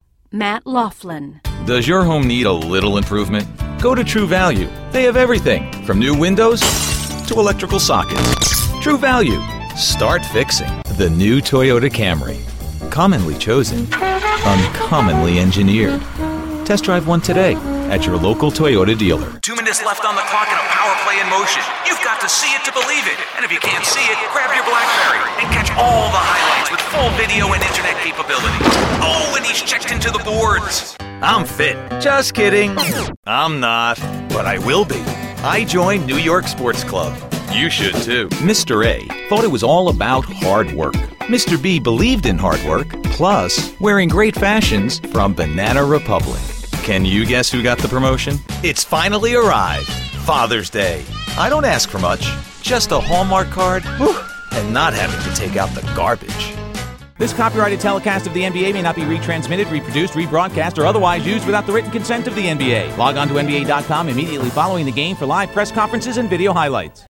commercial : men